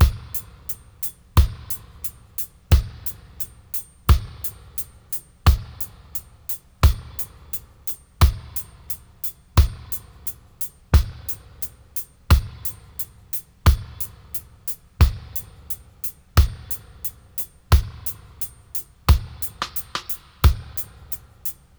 88-FX-02.wav